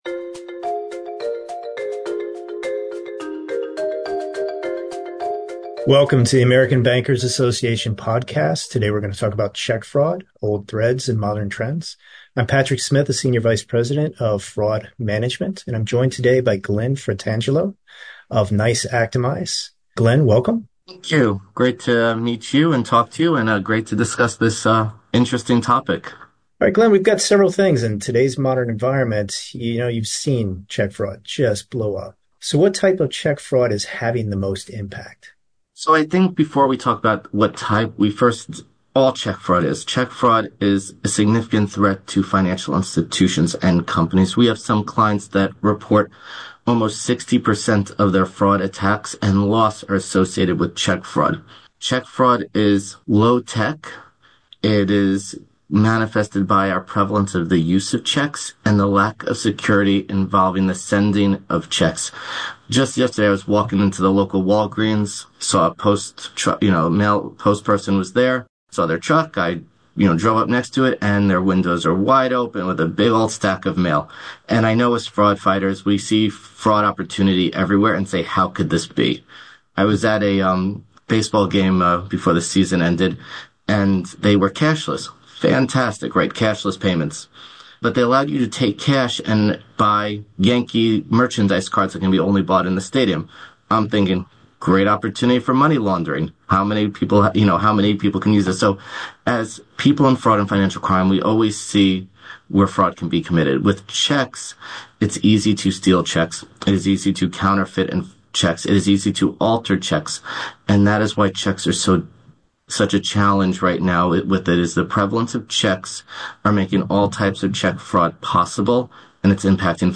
ABA Podcast: “60% of Fraud Attacks and Losses Associated with Check Fraud”